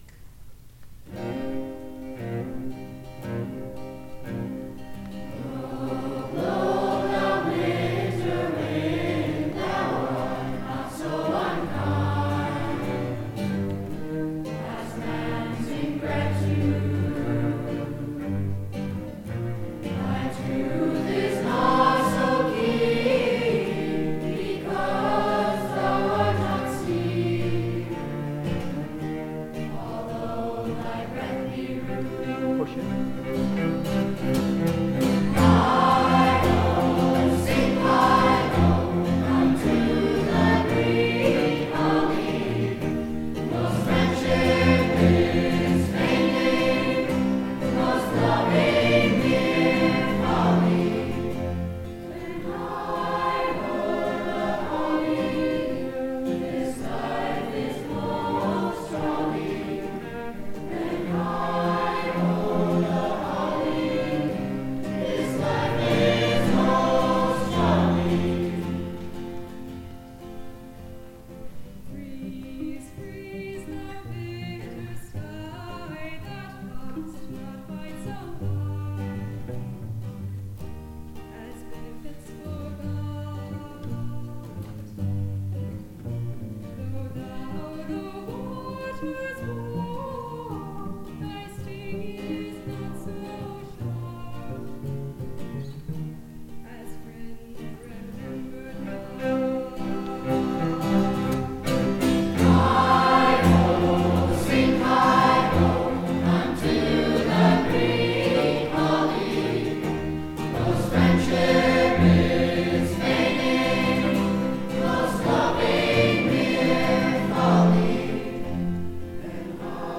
Chamber, Choral & Orchestral Music
2:00 PM on August 13, 2017, St. Mary Magdalene
Chorus